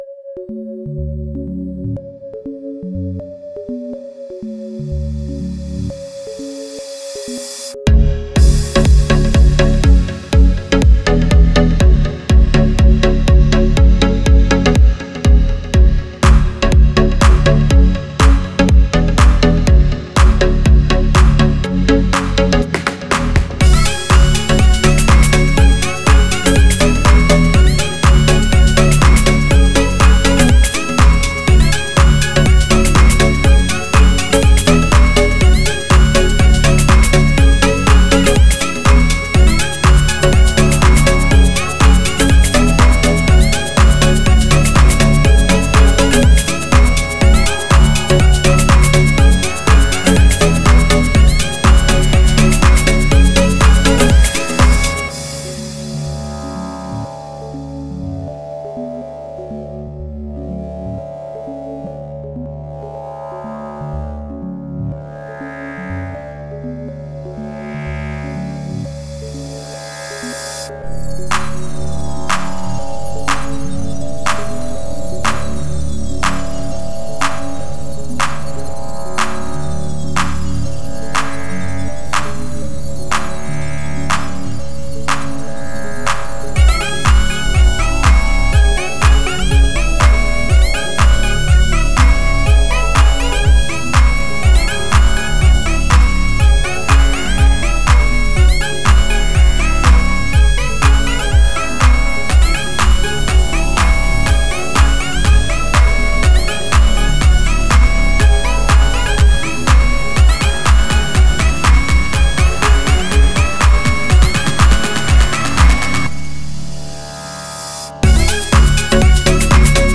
это атмосферная композиция в жанре chillwave